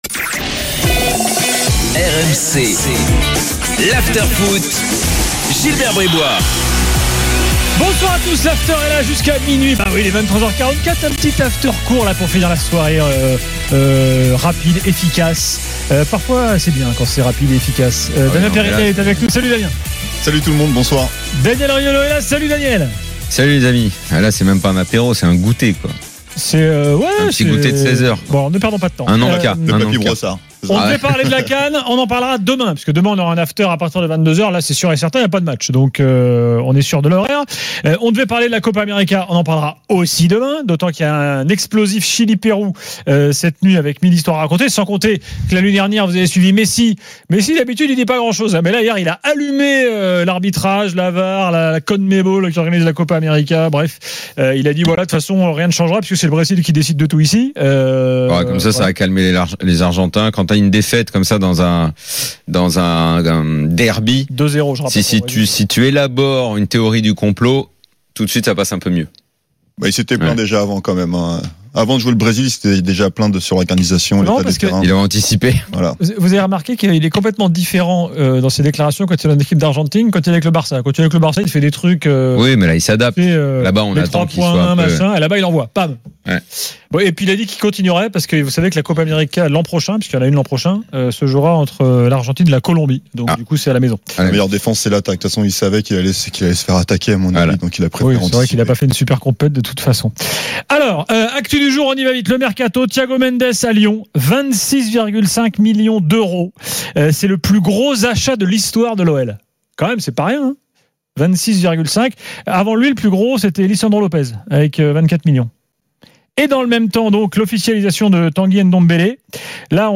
Après le match, avec Gilbert Brisbois, Daniel Riolo et Jérôme Rothen, le micro de RMC est à vous !